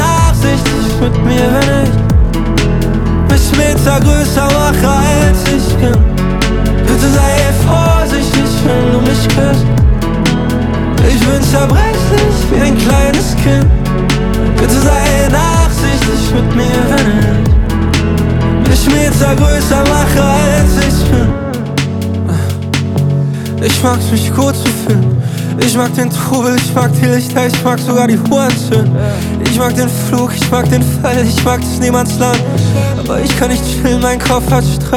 Жанр: Хип-Хоп / Рэп / Поп музыка